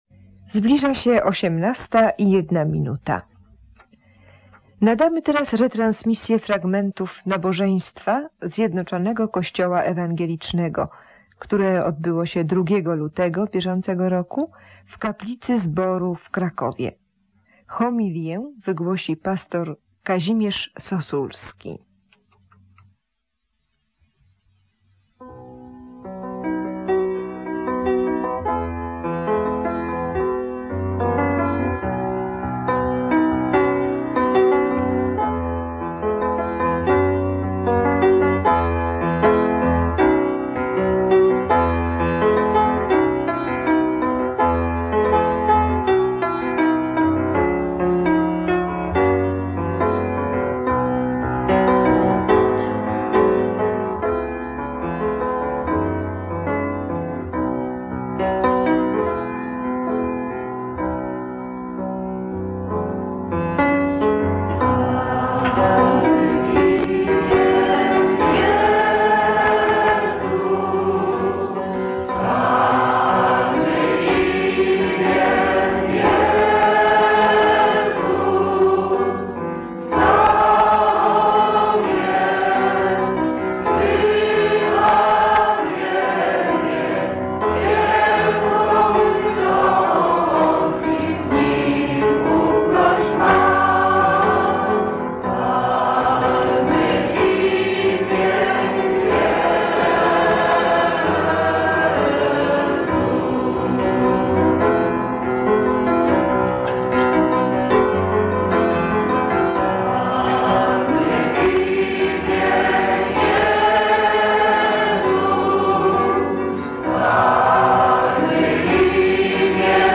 Transmisja radiowa – nabożeństwo w Zborze Betlejem w Krakowie